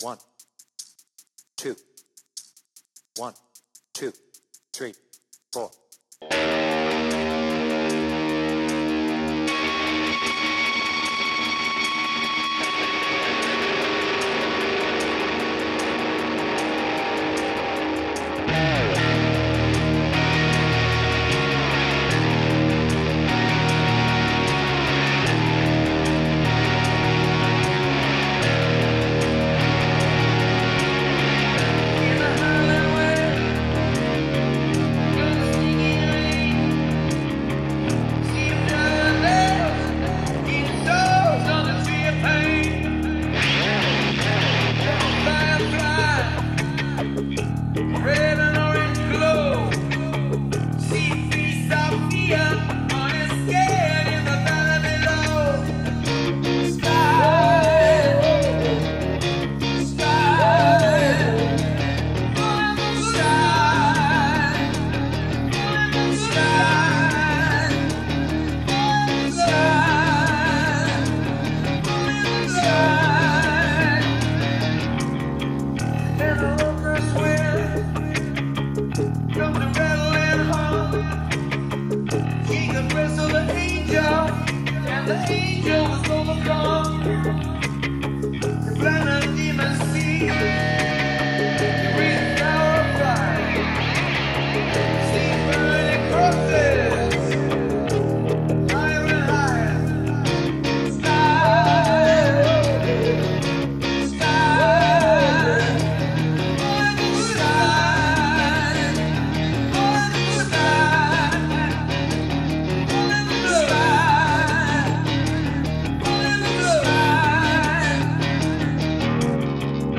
BPM : 76
Tuning : Eb